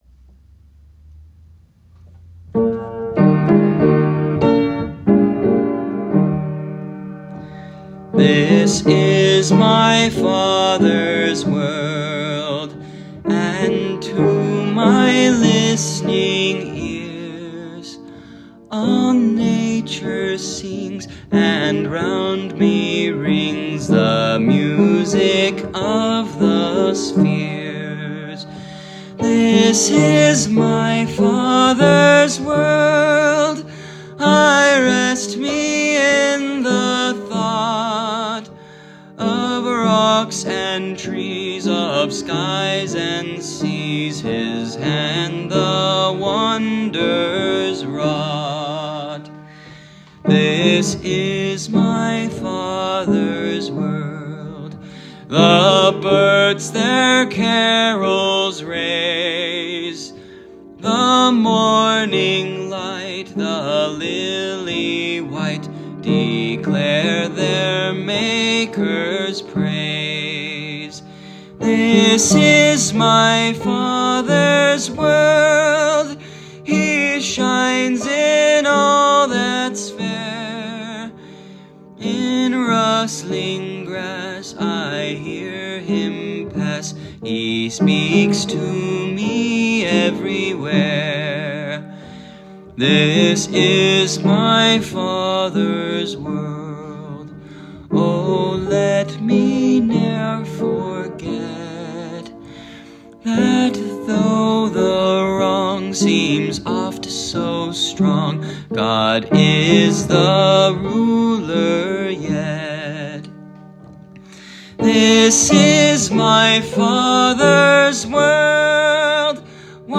Listen to the hymn, follow the sheet music, or download both files.